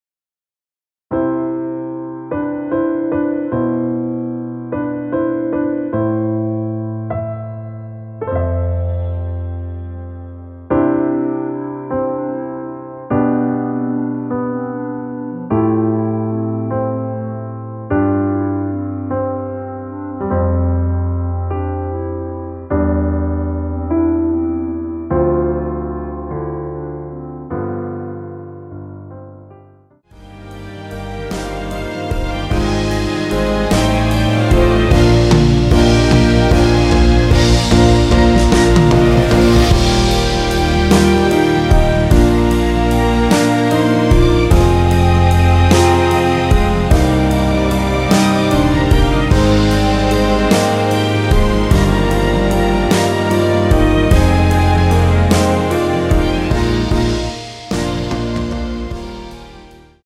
원키에서(-5)내린멜로디 포함된 MR입니다.
앞부분30초, 뒷부분30초씩 편집해서 올려 드리고 있습니다.